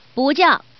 Index of /hunan_feature2/update/12623/res/sfx/doudizhu_woman/